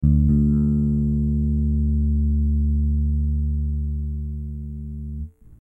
Звуки бас-гитары
Длинный звук бас-гитарной струны